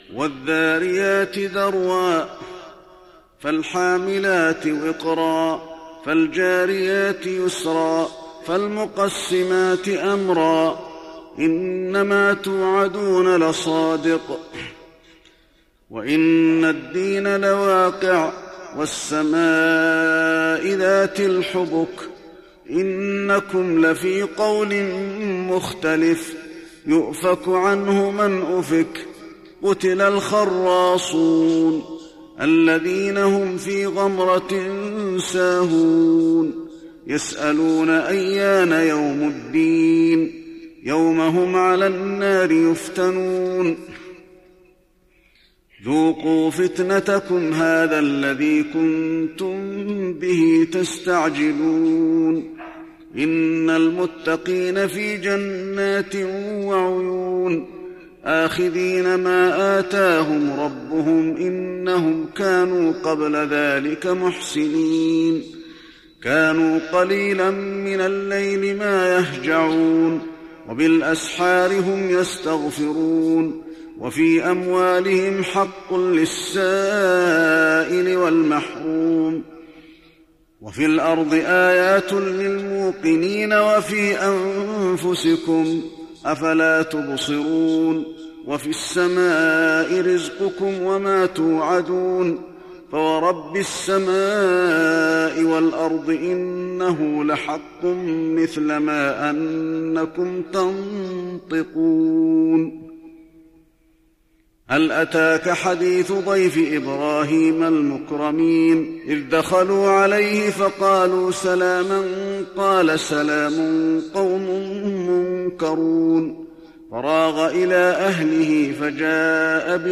تراويح رمضان 1415هـ من سورة الذاريات الى سورة الرحمن Taraweeh Ramadan 1415H from Surah Adh-Dhaariyat to Surah Ar-Rahmaan > تراويح الحرم النبوي عام 1415 🕌 > التراويح - تلاوات الحرمين